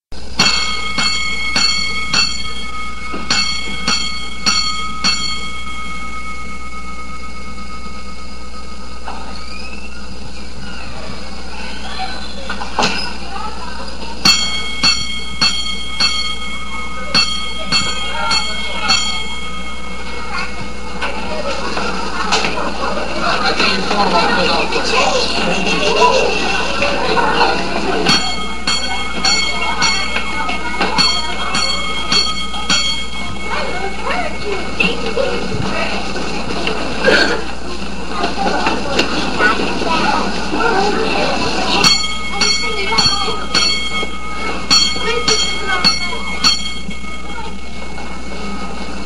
These are sound clips from the Mechanicville Middle, High, and Elementary schools.
Old Elementary School
Fire Alarm System
ES_Fire_Alarm.mp3